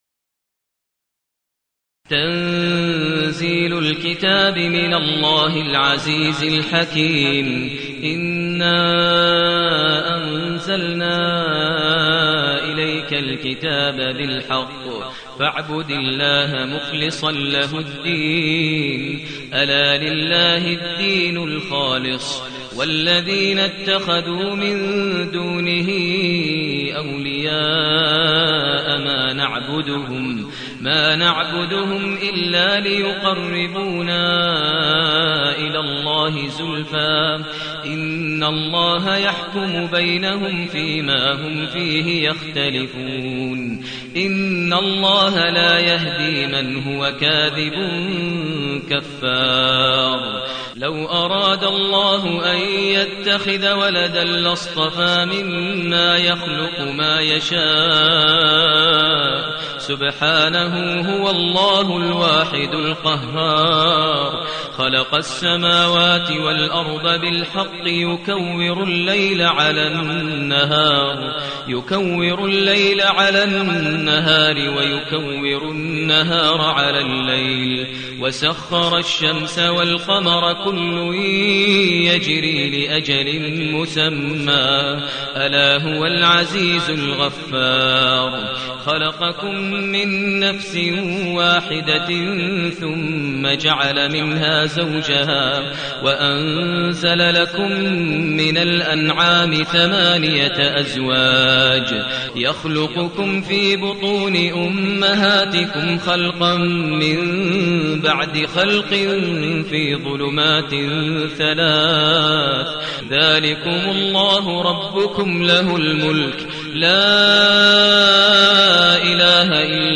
المكان: المسجد النبوي الشيخ: فضيلة الشيخ ماهر المعيقلي فضيلة الشيخ ماهر المعيقلي الزمر